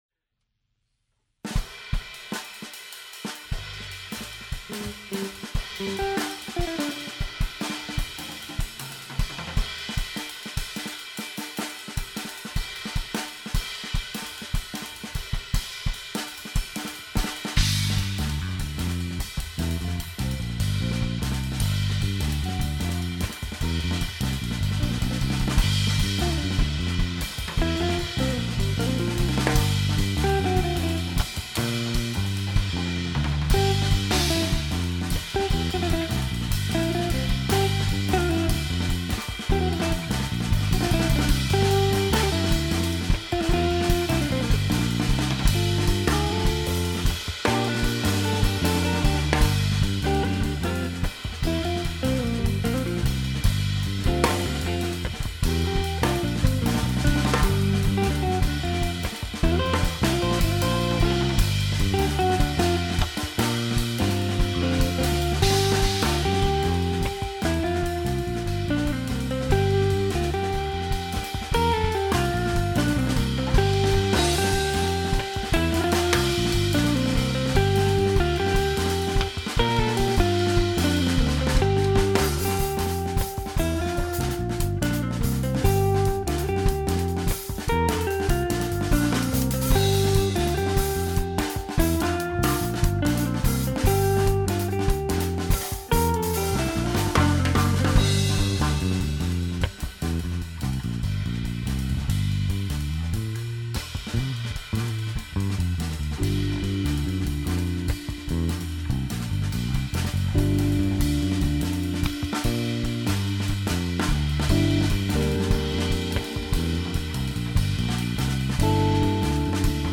Cik Cak je bend v katerem igram bas kitaro; posneli smo nekaj demo posnetkov; najprej je bil nared prvi z imenom pet v stilu jazz-fusion-funk-rock-indie-metal-balkan-swing-elektra; neki tazga